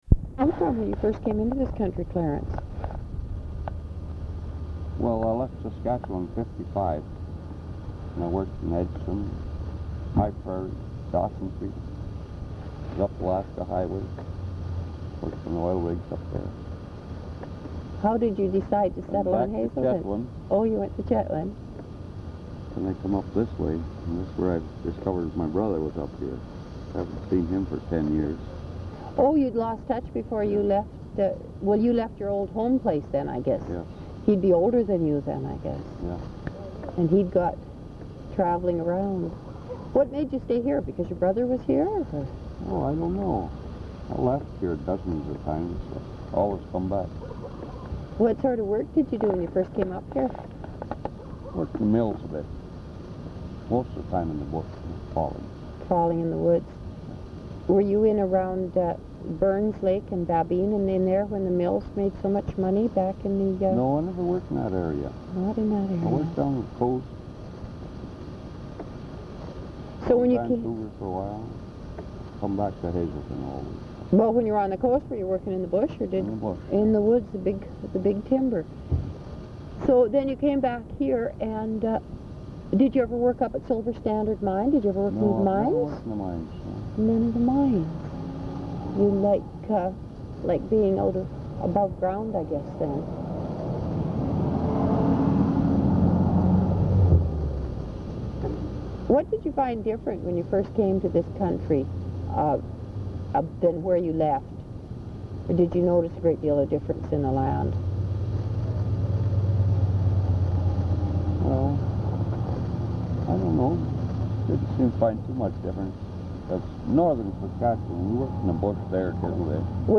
Audio non-musical
oral histories (literary works)